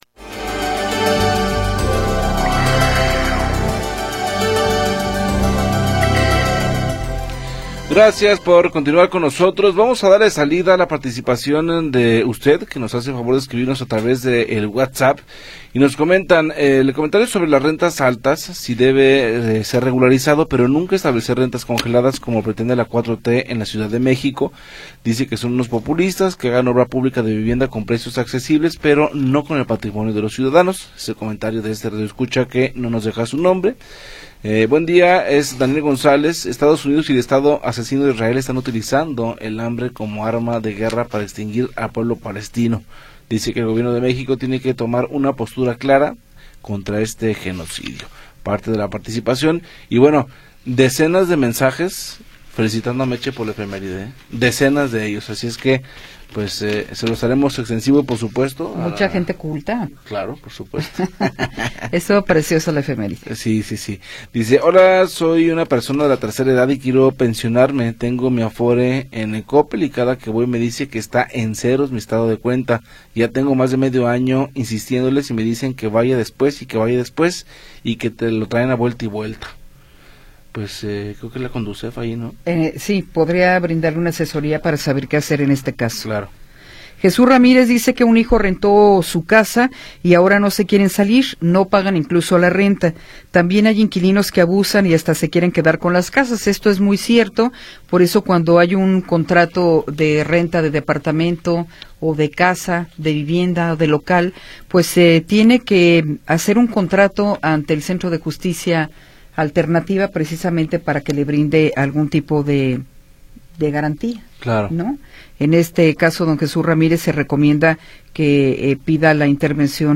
Información oportuna y entrevistas de interés